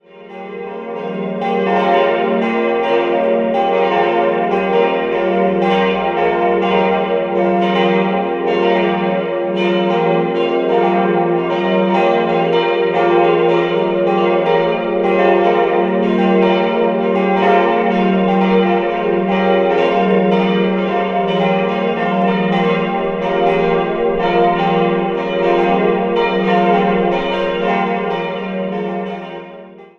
4-stimmiges Gloria-TeDeum-Geläute: f'-g'-b'-c'' Die drei größeren Glocken stammen aus dem Jahr 1961 von Rudolf Perner aus Passau.
Sie konnte jedoch erst im Dezember 2015 im Turm installiert werden und erklingt seitdem in angenehmer Harmonie mit den drei anderen Glocken.